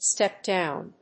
アクセントstép‐dòwn